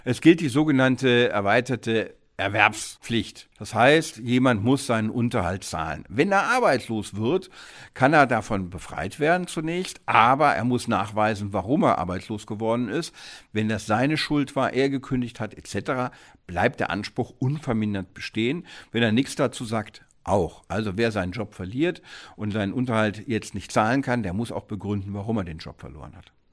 O-Ton: Arbeitslosigkeit schützt nicht automatisch vor Unterhaltspflichten – Vorabs Medienproduktion